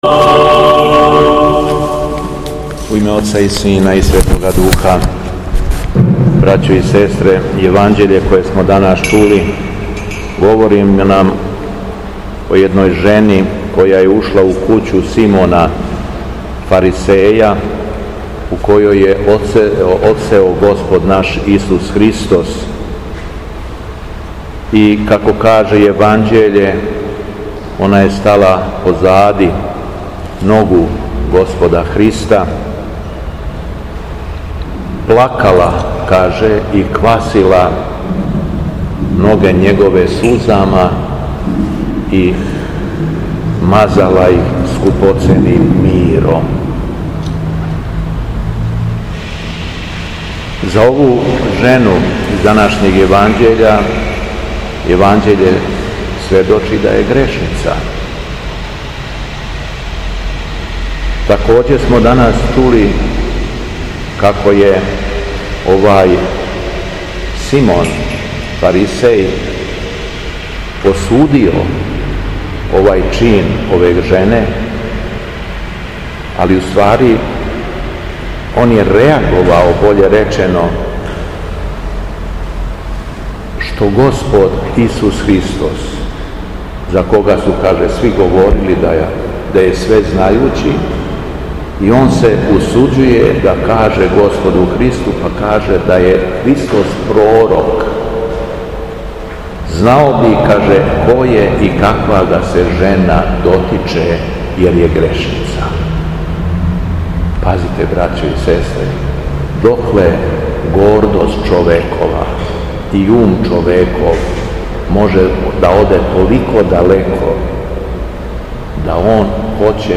Беседа Његовог Високопреосвештенства Митрополита шумадијског г. Јована
После прочитаног јеванђелског зачала од јеванђелисте Луке о помазању Христа од жене грешнице, преосвећени владика се обратио беседом сабраном народу: